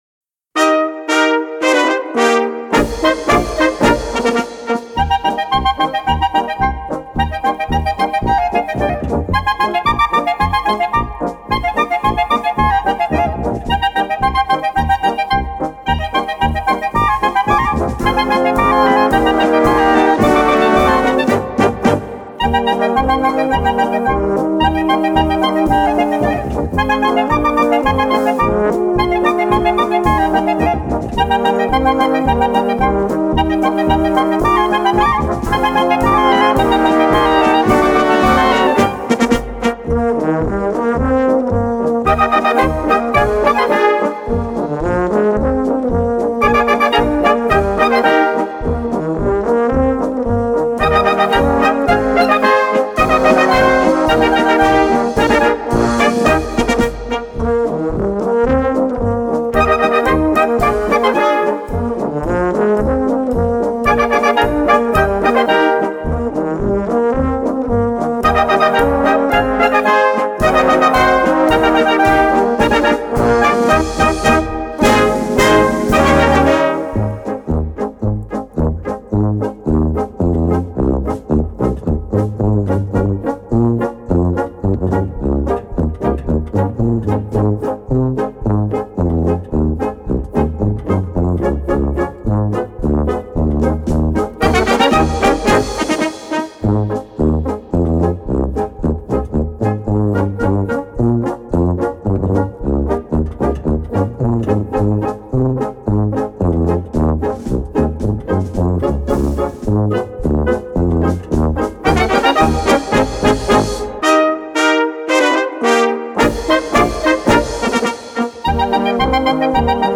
Category Concert/wind/brass band
Subcategory Polka
Instrumentation Ha (concert/wind band)